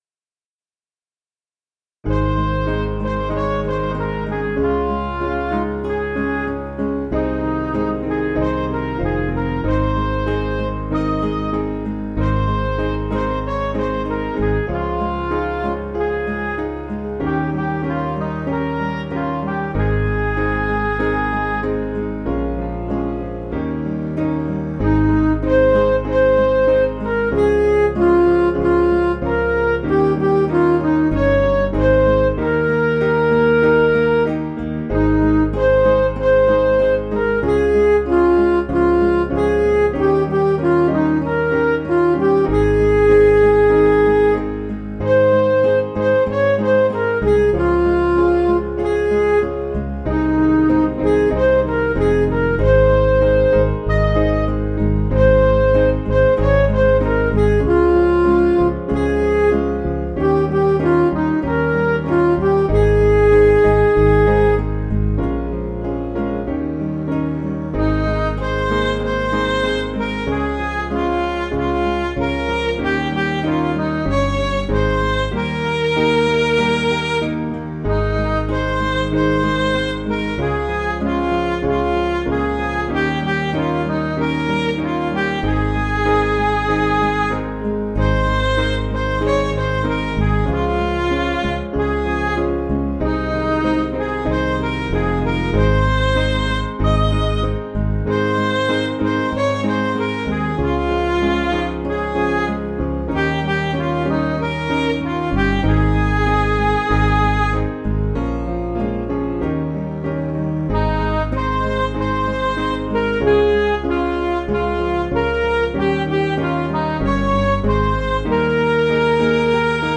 Piano & Instrumental
(CM)   4/Ab